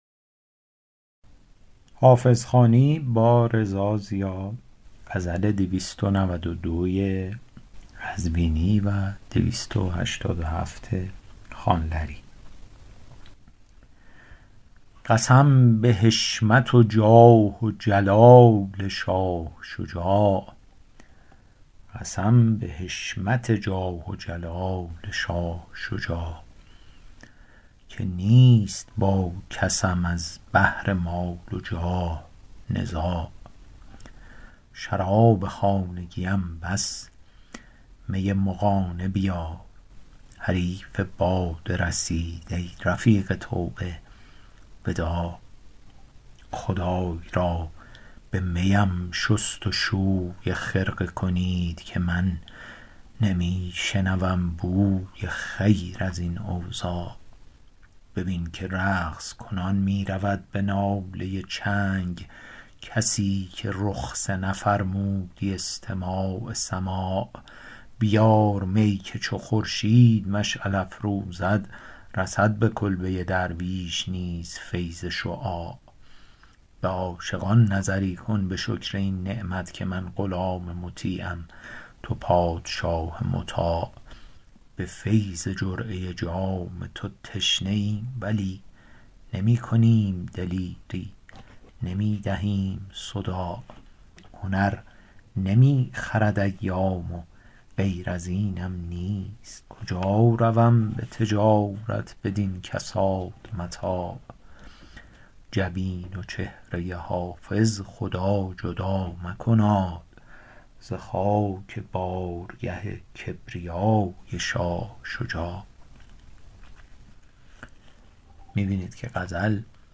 حافظ غزلیات شرح صوتی غزل شمارهٔ ۲۹۲